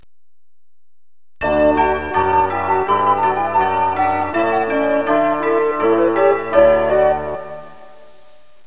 1 channel
sbbells.mp3